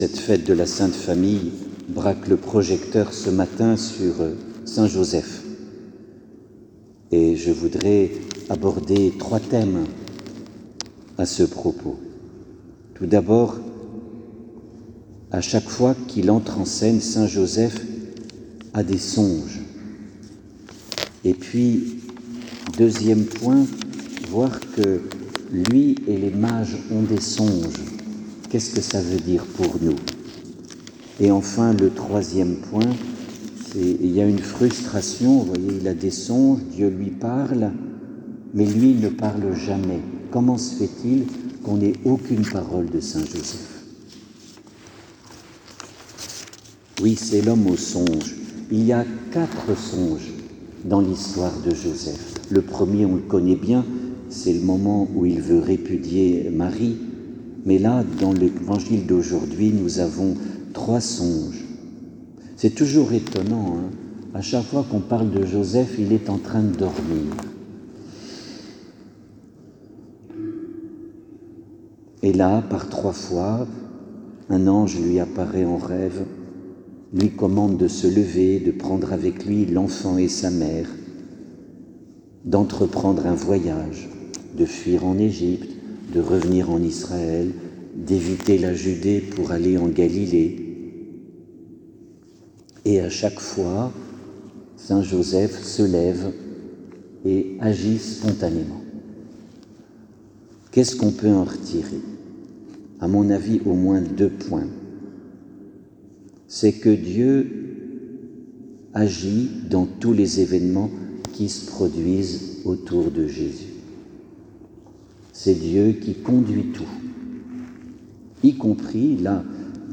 Homelie-la-Sainte-Famille.mp3